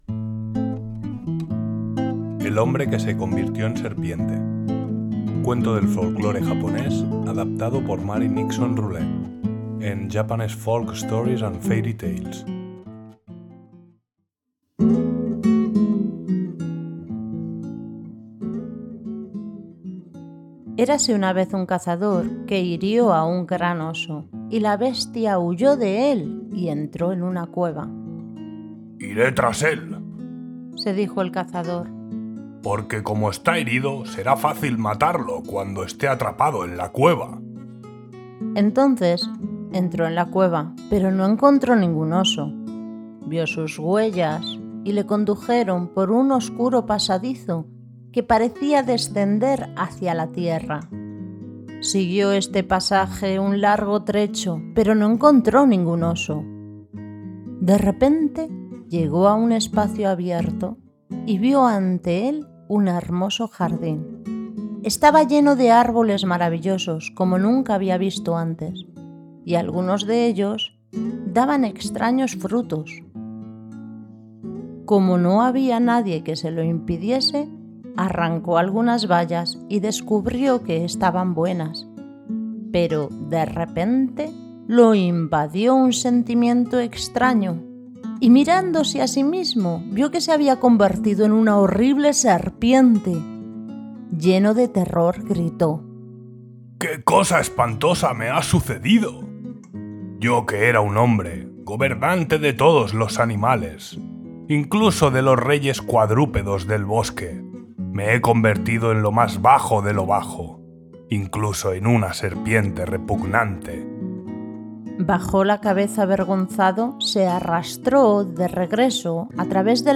🎴🐍-el-hombre-que-se-convirtio-en-serpiente-🏮-cuento-japones-🎶-musica-tradicional-🎨-arte-de-hokusai.mp3